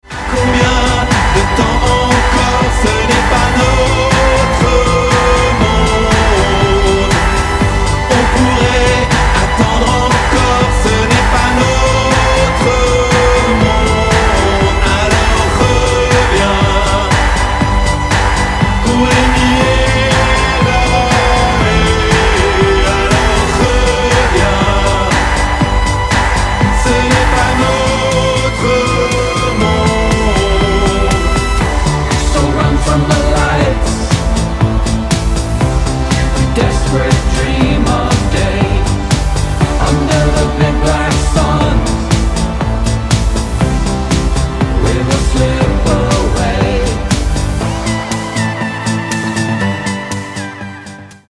• Качество: 320, Stereo
мужской голос
атмосферные
спокойные
космические